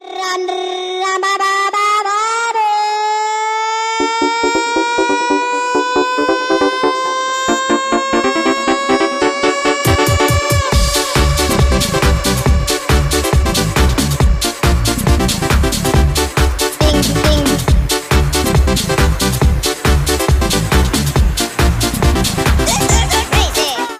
громкие
веселые
Electronic
Euro House